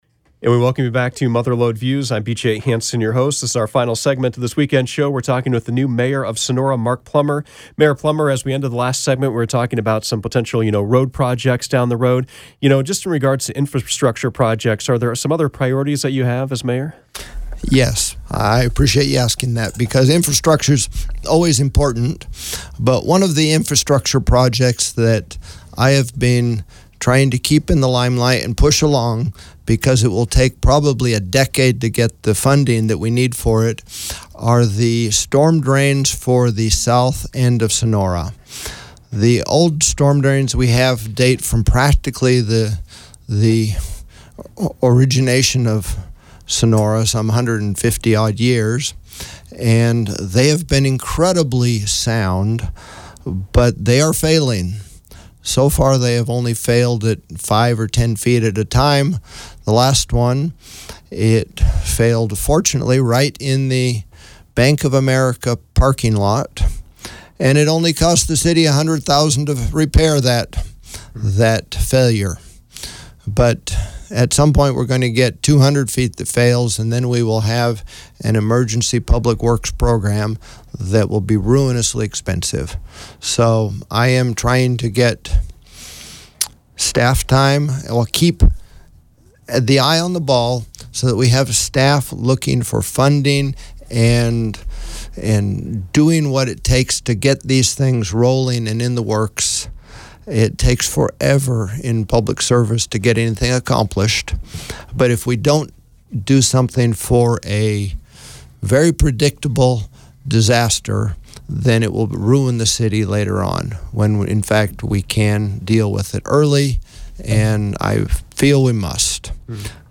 Mother Lode Views featured the new Mayor of Sonora, Mark Plummer.